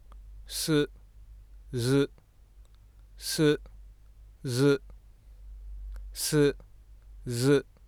ここには「ス」と「ズ」の発音が含まれています（ただし，後述するように日本語の「ズ」の子音は摩擦音で現れることもあれば破擦音で現れることもあります。ここでは意図的に摩擦音で発音しています）。
上図の二つの発話の子音部分のスペクトログラムを比べると，右（「ズ」）では高周波数域のみならず低周波数域にもエネルギーが分布していることがわかると思います。